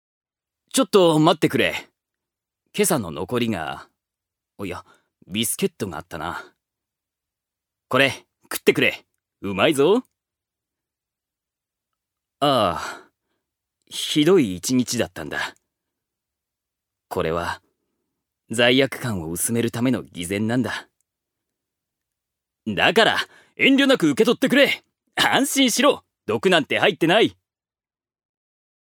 預かり：男性
セリフ５